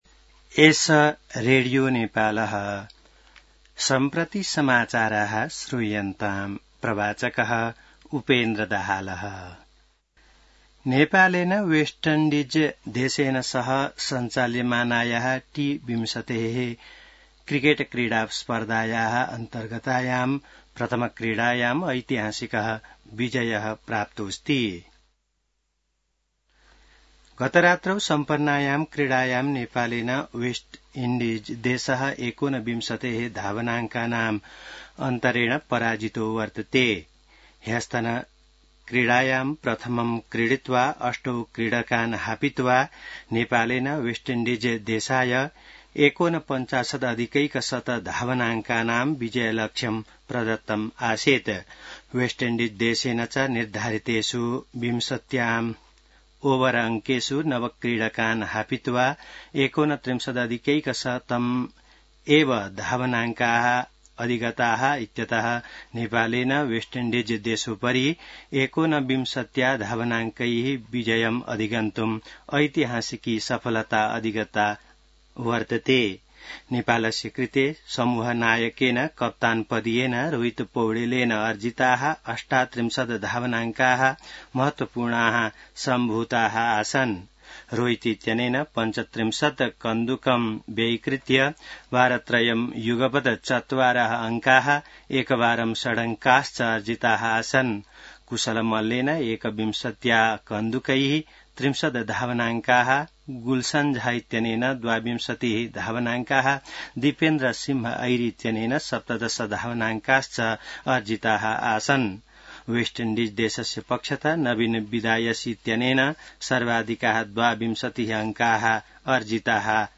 संस्कृत समाचार : १२ असोज , २०८२